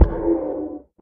守卫者：受伤
守卫者在水中受伤
Minecraft_guardian_guardian_hit2.mp3